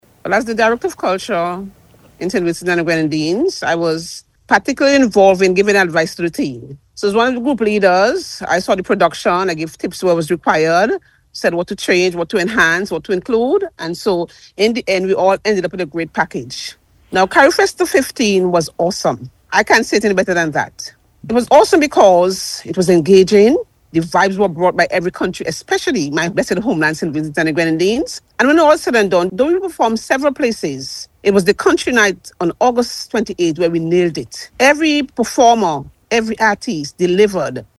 Speaking on NBC Radio’s Talk Yuh Talk programme